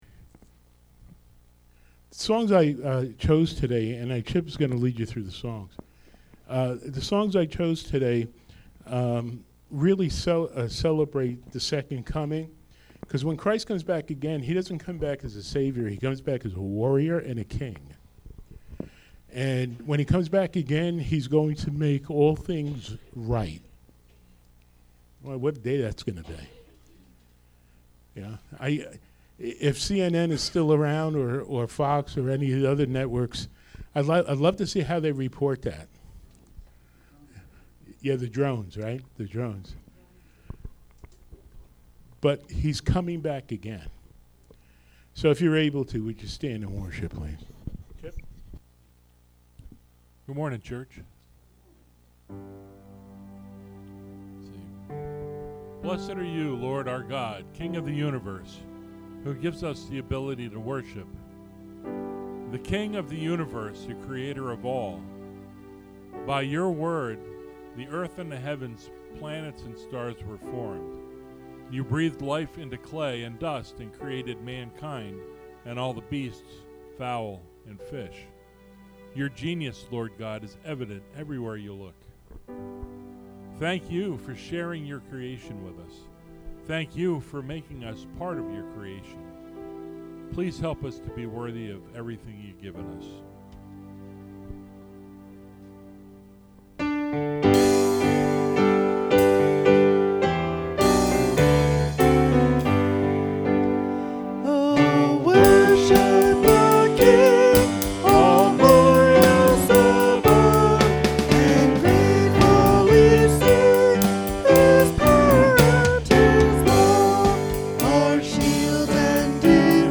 Podcast (sermons): Play in new window | Download